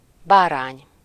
Ääntäminen
Ääntäminen France: IPA: [ɛ̃.n‿a.ɲo] France (Paris): IPA: /a.ɲo/ Paris: IPA: [a.ɲo] Paris Haettu sana löytyi näillä lähdekielillä: ranska Käännös Ääninäyte Substantiivit 1. bárány Suku: m .